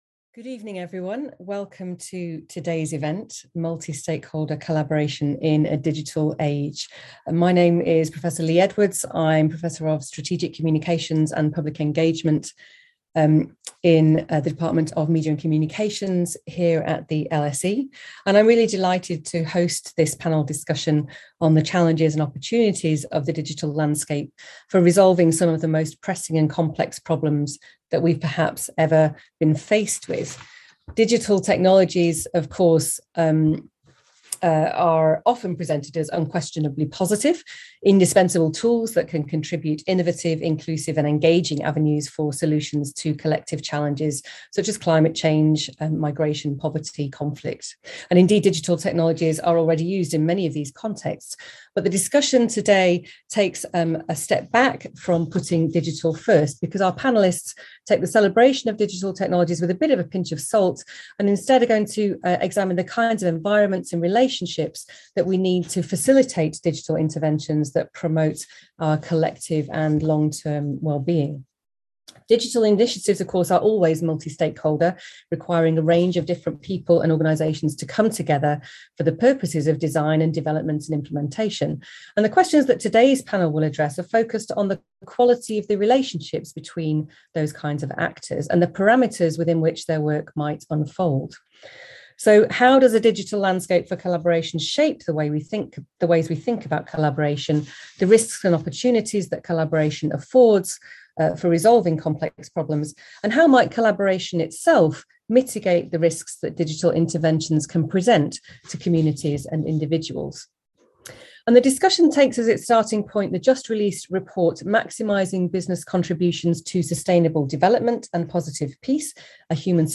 Policy, business and activist leaders discuss the challenges and opportunities of using technology in collective efforts to resolve complex problems.